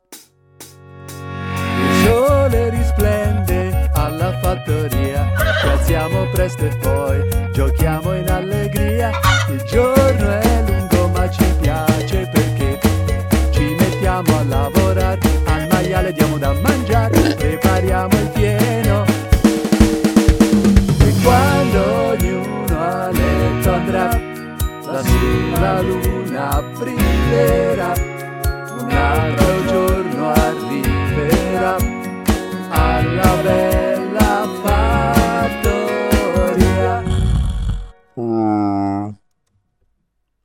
Commercial
Male
English with International Accent
Friendly
Gravitas
Smooth
Warm